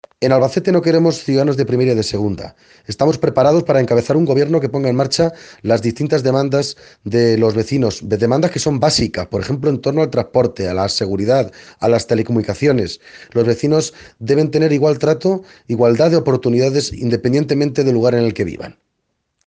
ha hecho estas declaraciones este domingo durante su visita al mercadillo de la entidad local menor de Aguas Nuevas